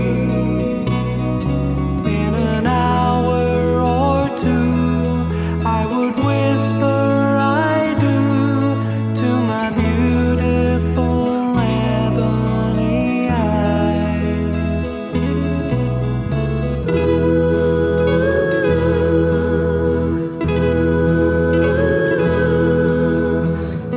No Harmony Pop (1950s) 3:04 Buy £1.50